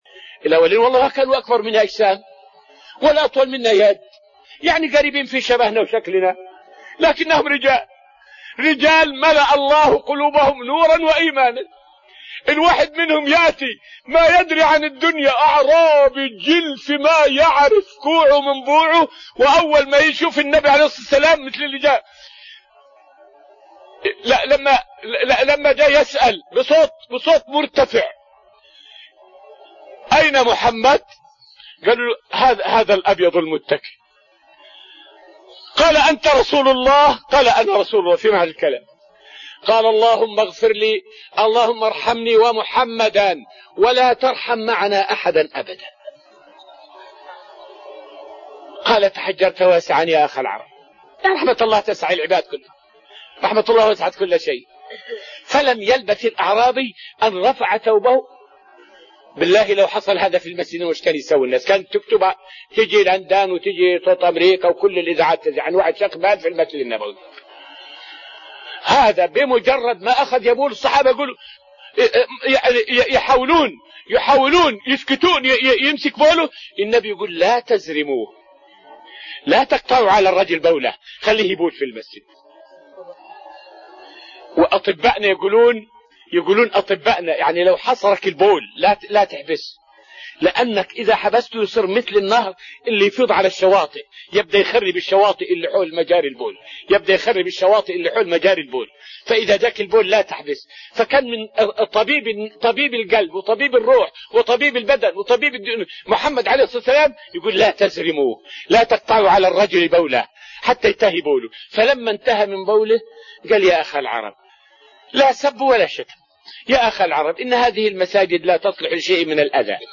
فائدة من الدرس العاشر من دروس تفسير سورة الأنفال والتي ألقيت في رحاب المسجد النبوي حول صور من رفق النبي صلى الله عليه وسلم.